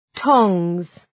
Προφορά
{tɒŋz}